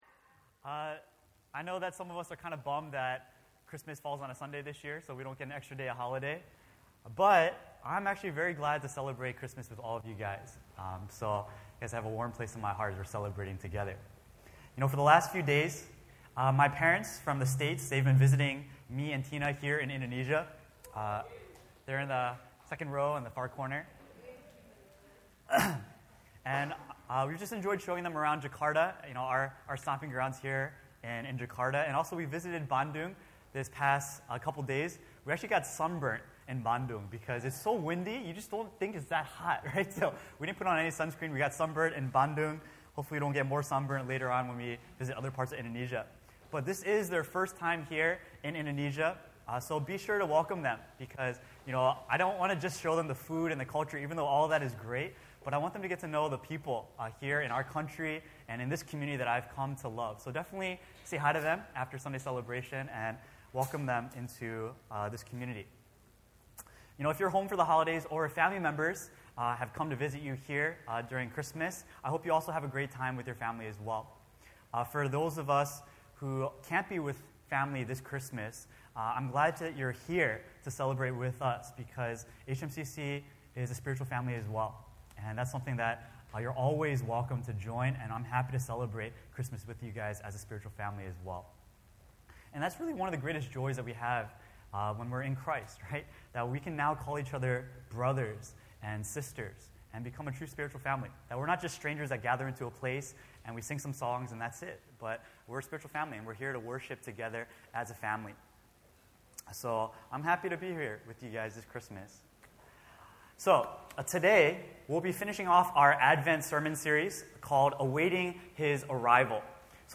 The Advent season is to commemorate Christ's arrival into our broken world. Throughout this sermon series, we’ll look into the Psalms and see how God’s people waited for a Savior.